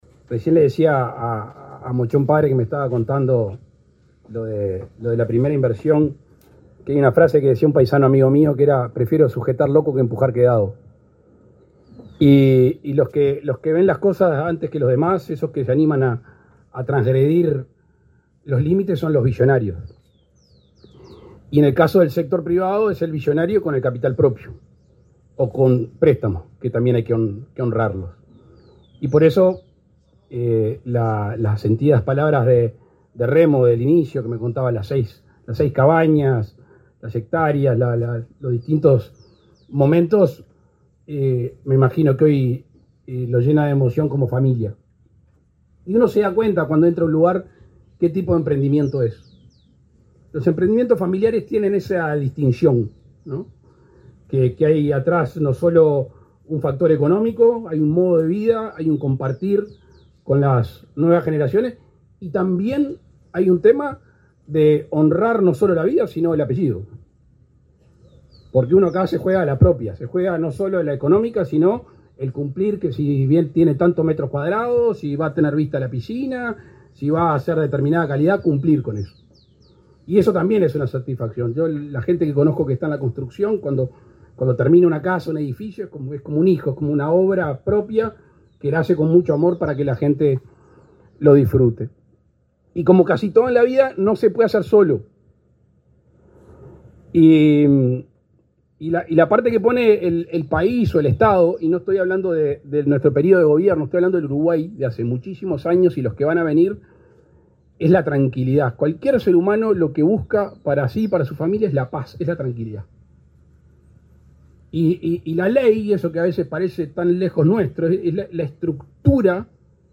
Palabras del presidente Luis Lacalle Pou
El presidente Lacalle Pou participó, este jueves 30, de la inauguración de Crystal View Punta del Este, en el complejo Solanas.